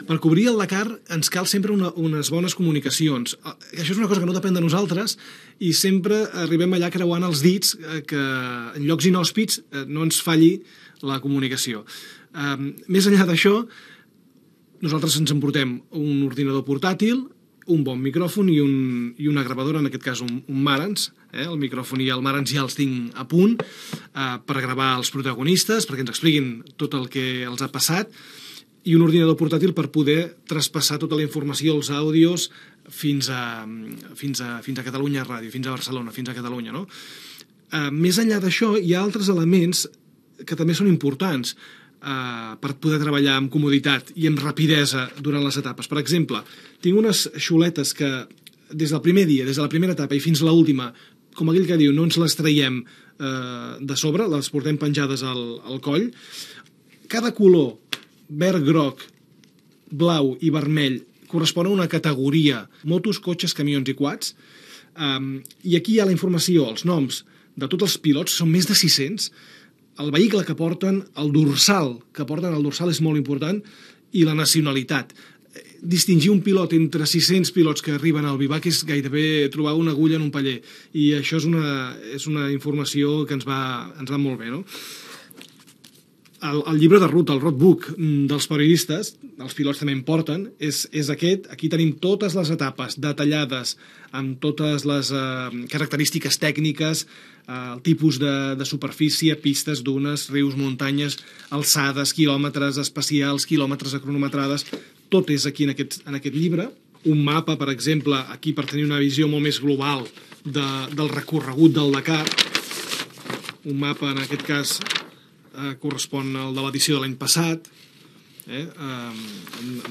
Explicació del material tecnològic i periodístic que es fa servir per fer la cobertura radiofònica del ral·li Dakar i dels criteris periodístics que es segueixen. Identificació de l'emissora